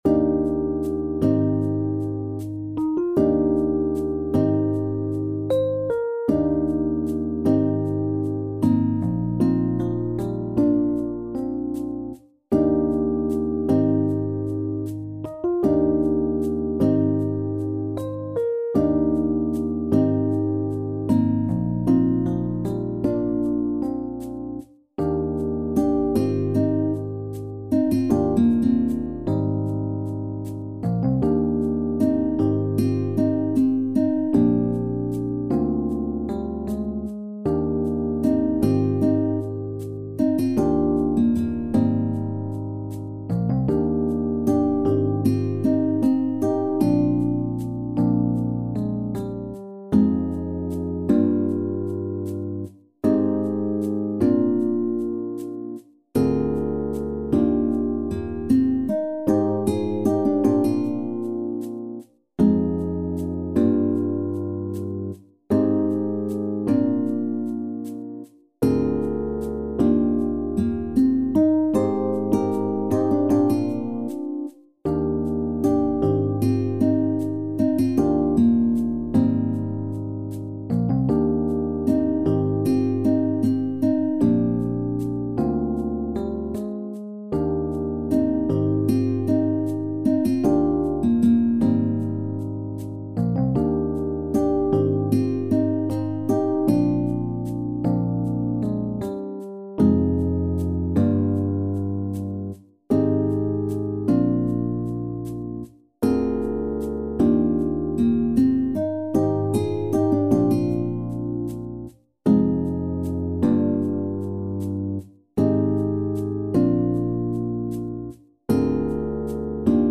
SSAATB | SSATTB | SSATB | SATTB | SSAATB (div.)
Een aangrijpend cabaretlied over een onmogelijke liefde